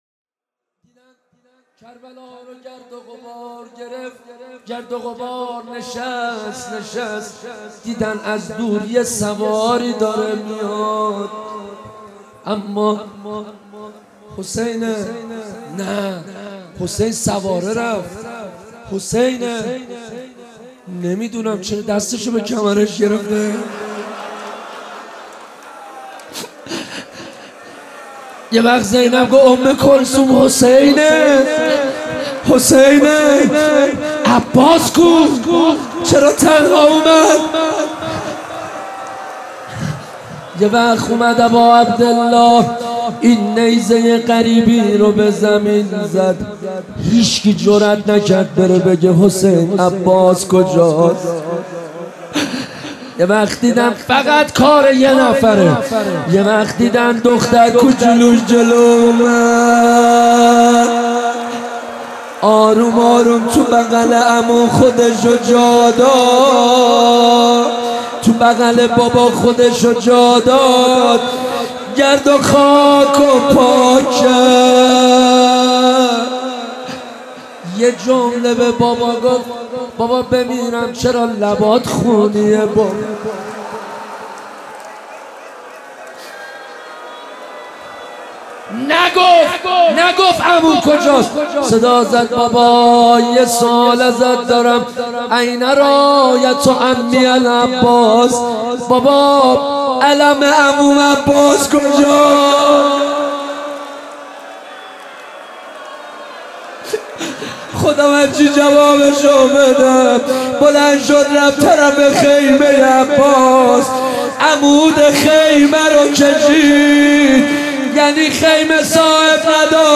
روضه حضرت ابالفضل